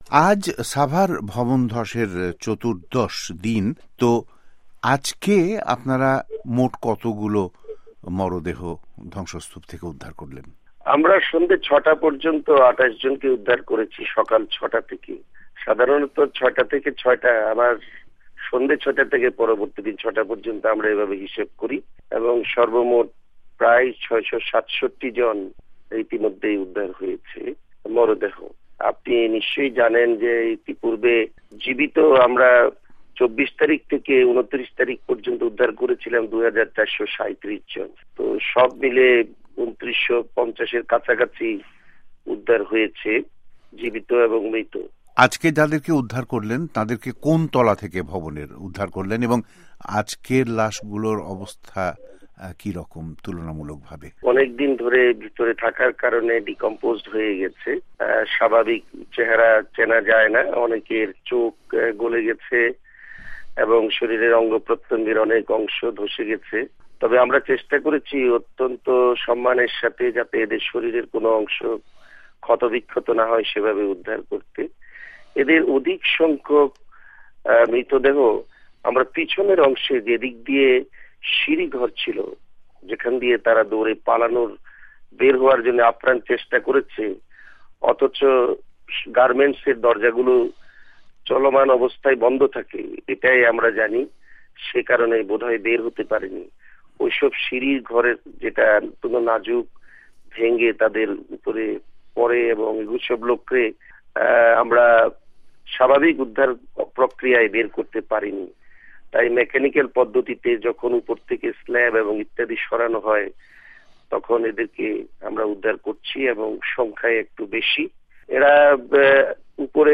সাভার ভবন ধসের উদ্ধার তত্পরতা নিয়ে মেজর জেনারেল হাসান সুহরাওয়ার্দির আলোচনা